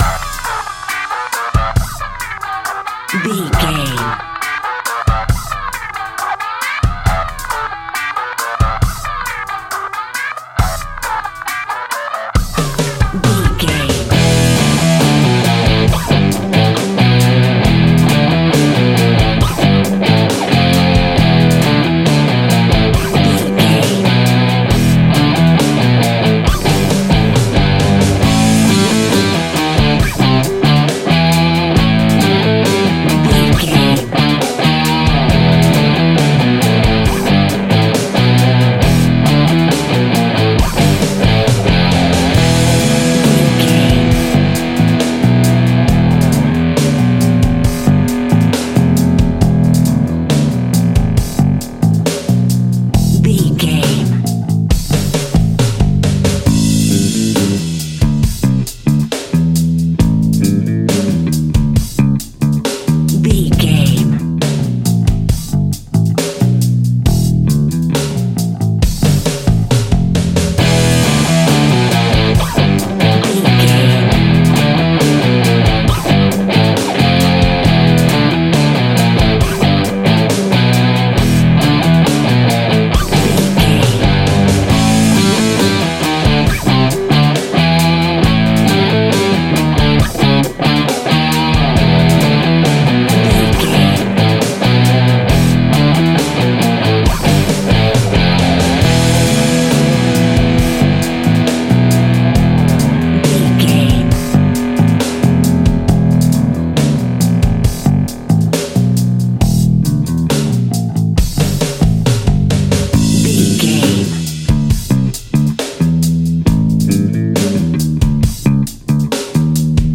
Epic / Action
Aeolian/Minor
hard rock
heavy metal
Heavy Metal Guitars
Metal Drums
Heavy Bass Guitars